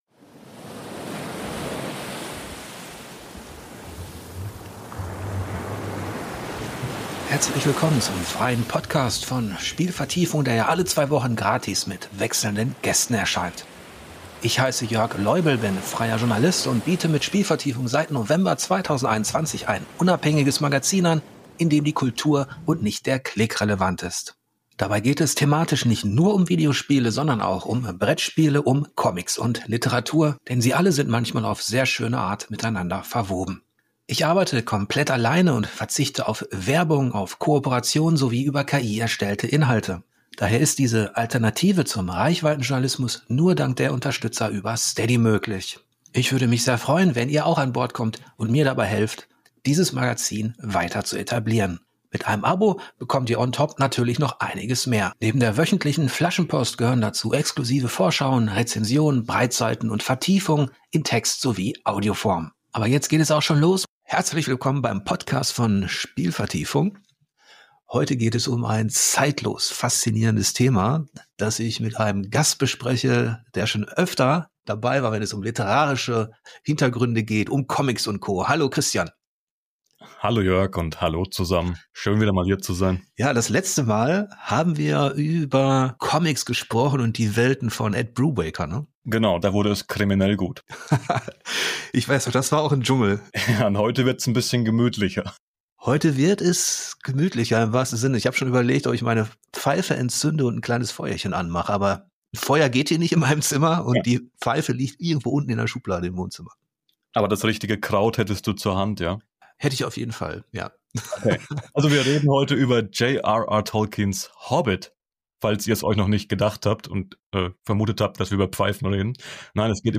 Ich spreche mit dem Schriftsteller